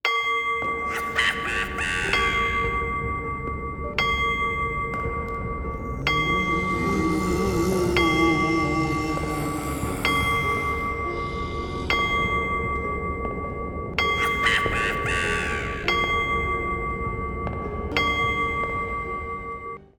cuckoo-clock-10.wav